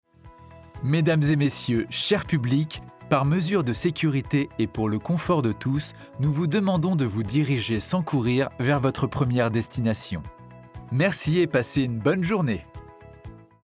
Docu Savane
- Baryton